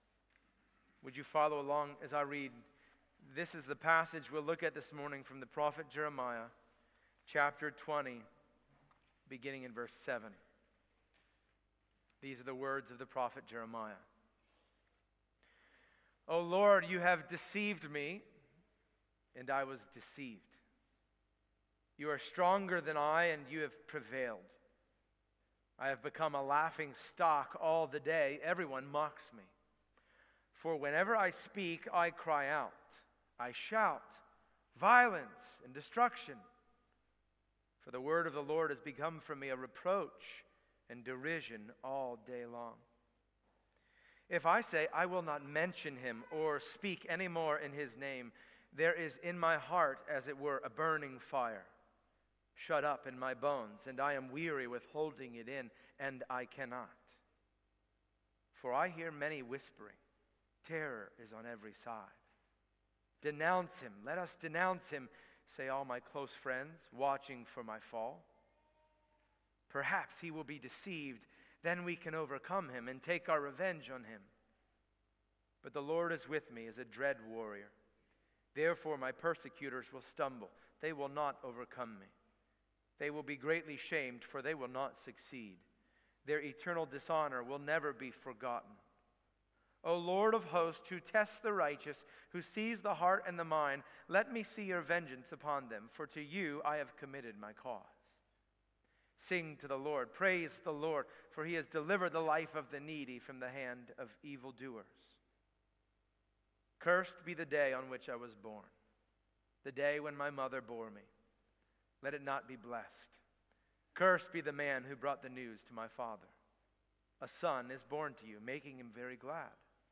Passage: Jeremiah 20:7-18 Service Type: Sunday Morning